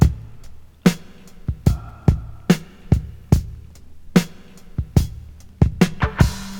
• 73 Bpm Drum Beat G Key.wav
Free drum beat - kick tuned to the G note. Loudest frequency: 640Hz
73-bpm-drum-beat-g-key-fCH.wav